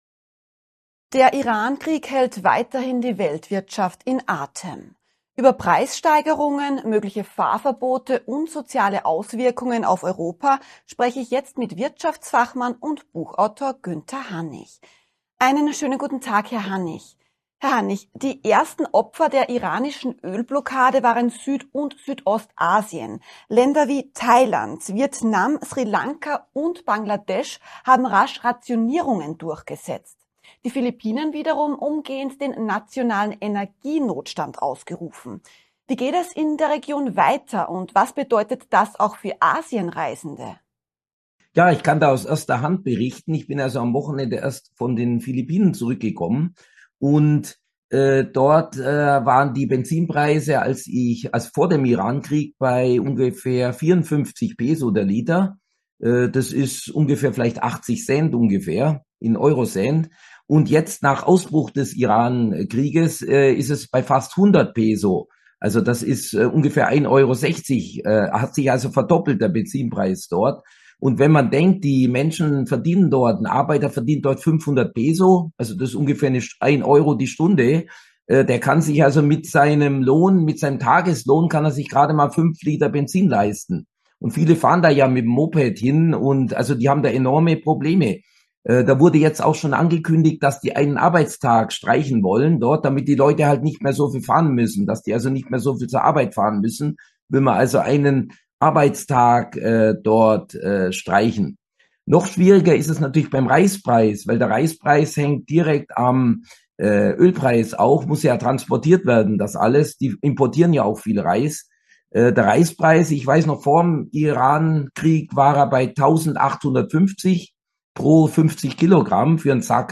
Brisantes Interview mit